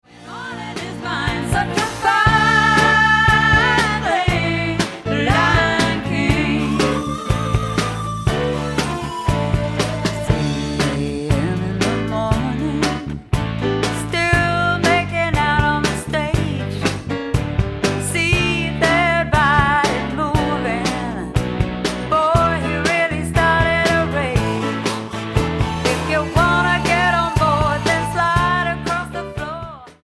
Genre / Stil: Country & Folk